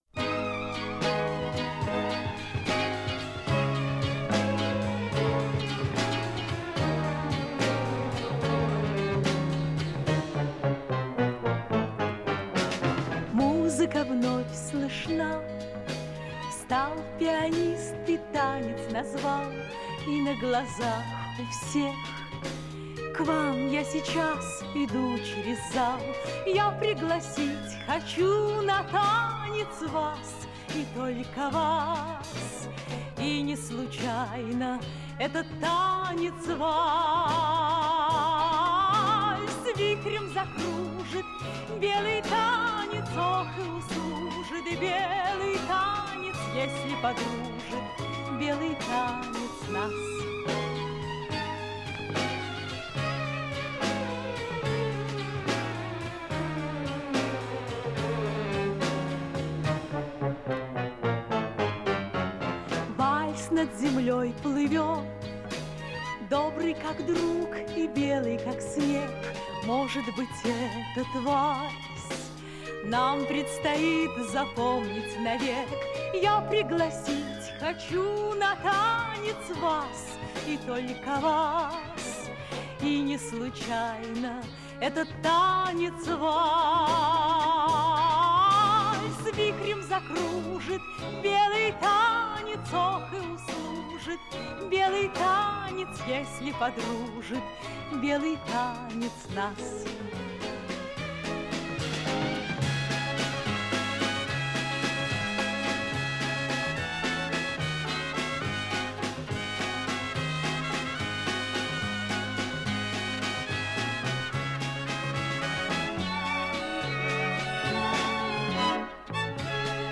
Описание: И не случайно: этот танец - вальс.